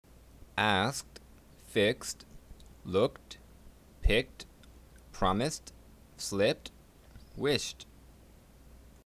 A Guide to Pronunciation
To listen to the above verbs ending in [t],